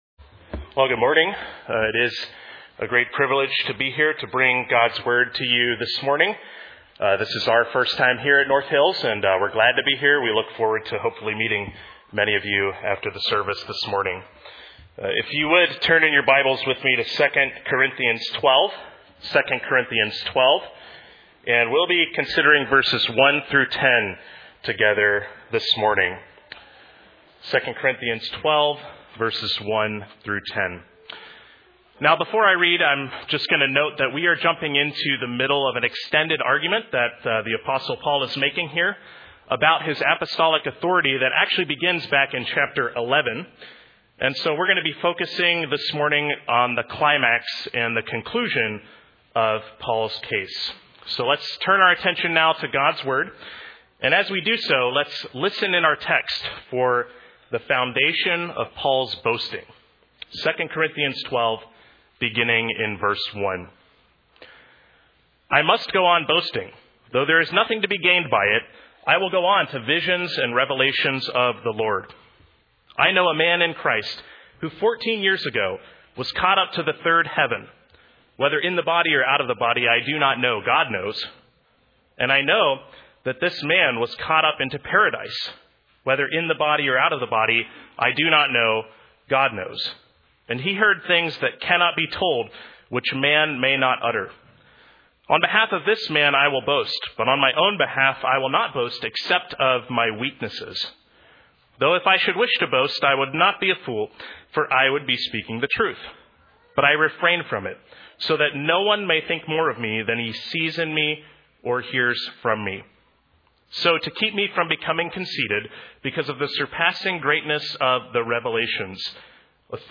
2 Corinthians 12:1-10 Service Type: Morning Rely on Christ’s power and grace in the midst of your weaknesses. 1.